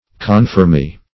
Meaning of confirmee. confirmee synonyms, pronunciation, spelling and more from Free Dictionary.
Search Result for " confirmee" : The Collaborative International Dictionary of English v.0.48: Confirmee \Con`fir*mee"\, n. [F. confirm['e], p. p. of confirmer.]